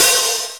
Closed Hats
Wu-RZA-Hat 51.wav